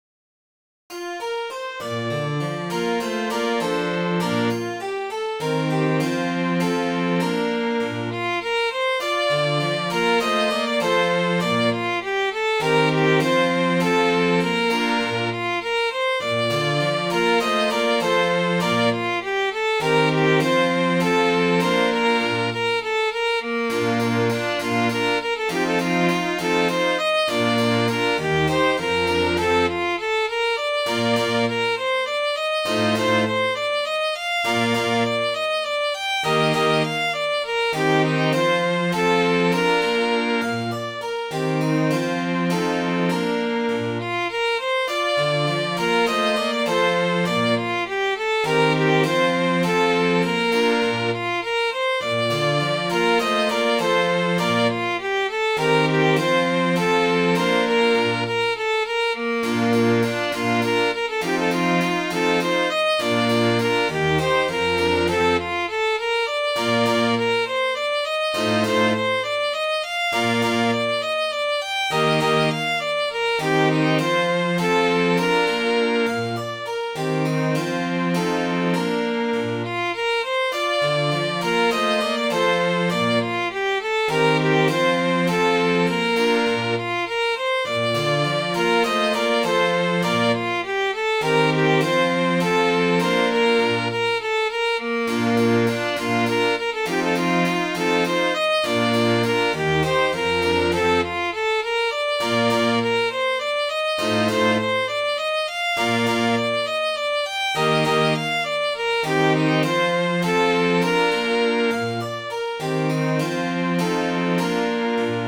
Midi File, Lyrics and Information to Ben Backstay
Ben Backstay (1) is a sea shanty which is also credited to Charles Dibdin.